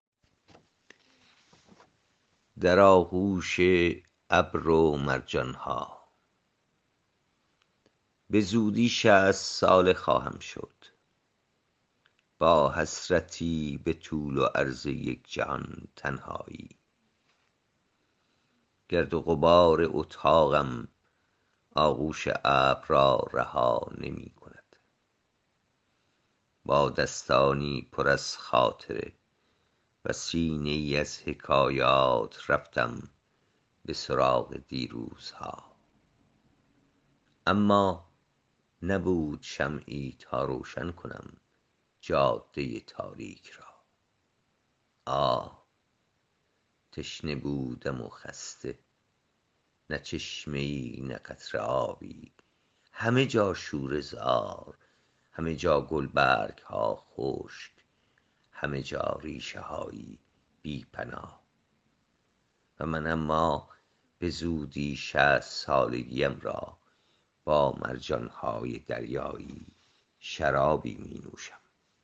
این شعر را با صدای شاعر از این جا بشنوید